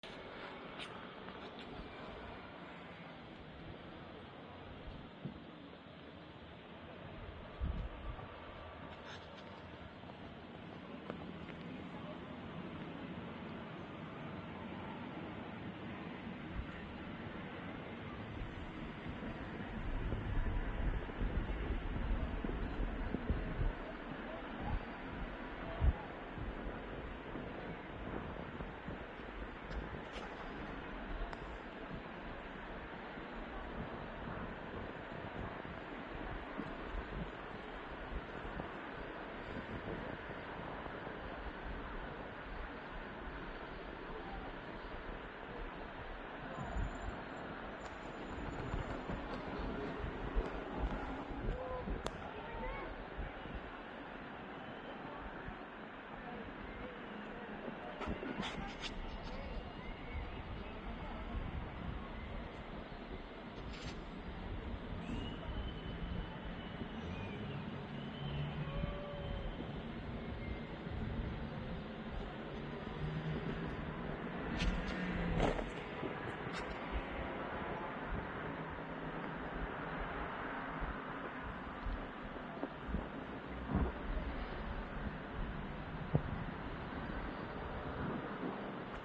תיעוד בלעדי משמי ירושלים מהדקות האחרונות